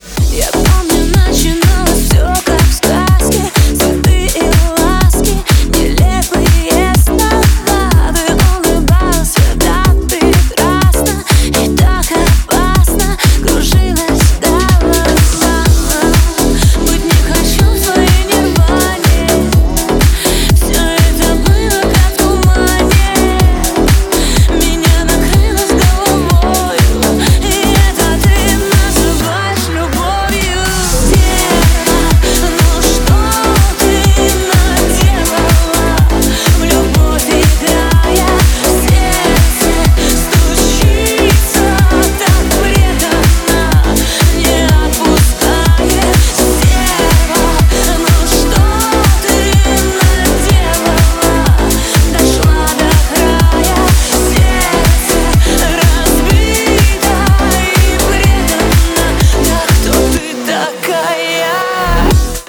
громкие
remix
deep house
Club House